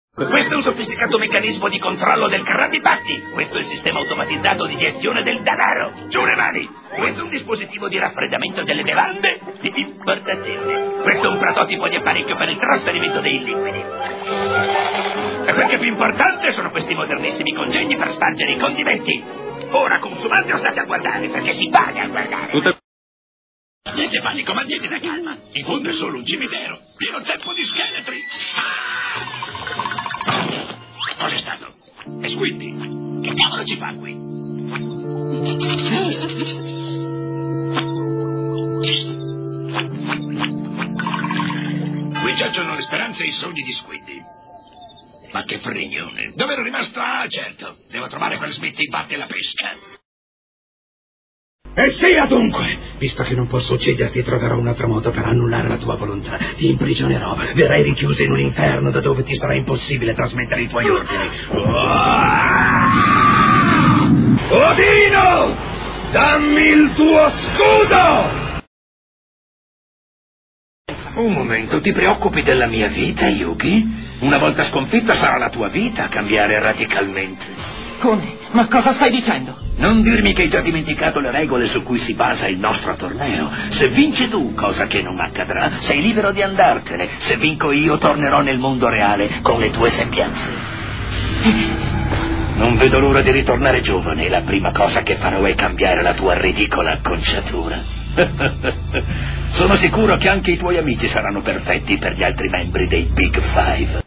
voce di Mario Zucca dai cartoni animati "SpongeBob", in cui doppia Mr. Kreb, e "Yu-Gi-Oh!", in cui doppia Gansli.